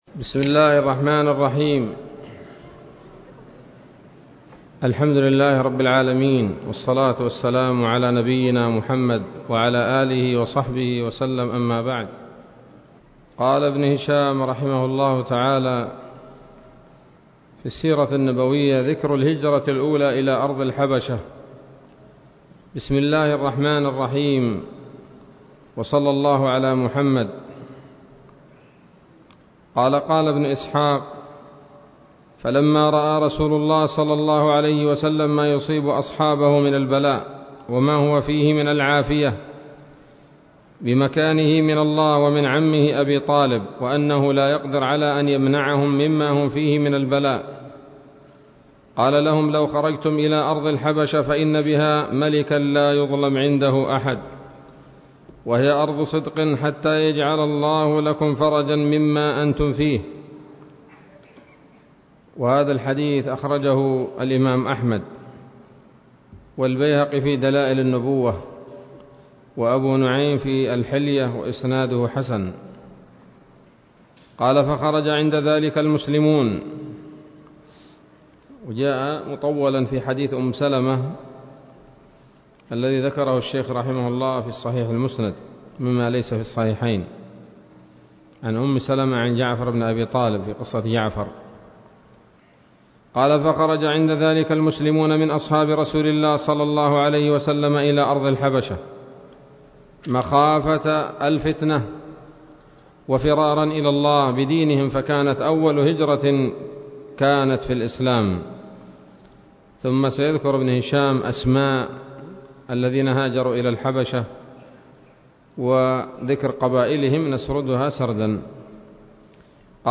الدرس الرابع والثلاثون من التعليق على كتاب السيرة النبوية لابن هشام